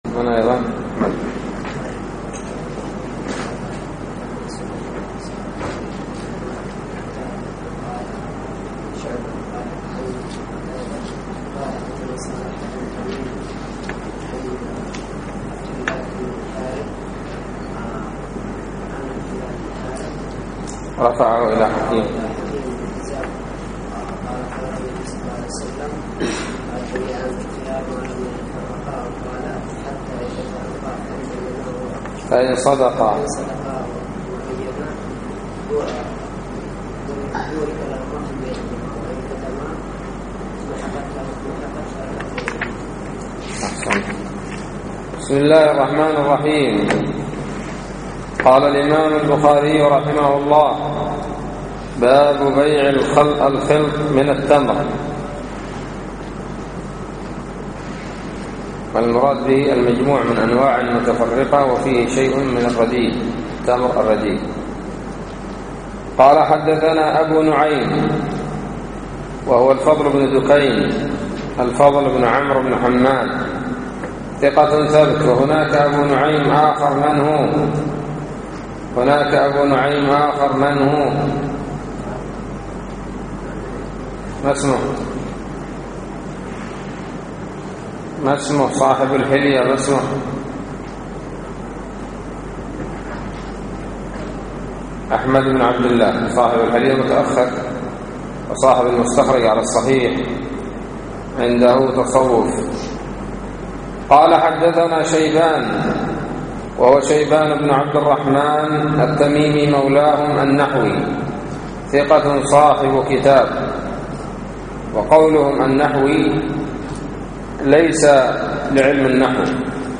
الدرس التاسع عشر :بَاب: بَيْعِ الْخِلْطِ مِنَ التَّمْرِ و بَاب: مَا قِيلَ فِي اللَّحَّامِ وَالْجَزَّارِ و بَاب: مَا يَمْحَقُ الْكَذِبُ وَالْكِتْمَانُ فِي الْبَيْعِ